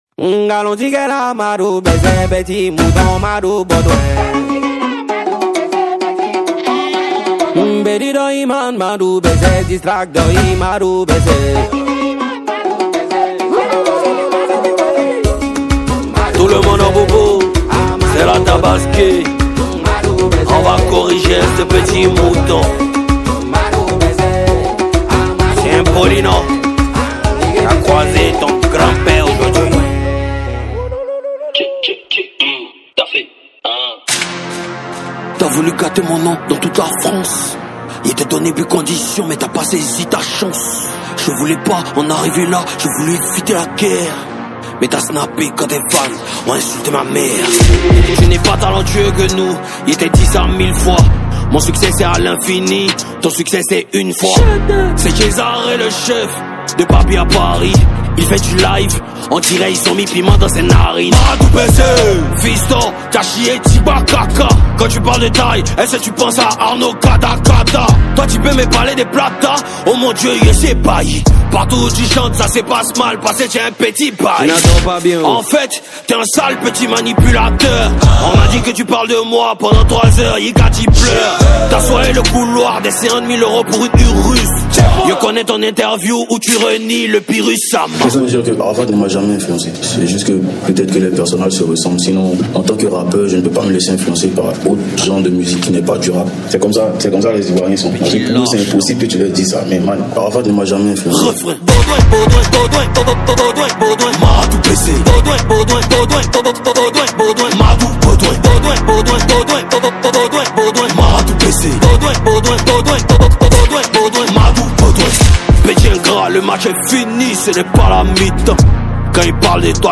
| Rap ivoire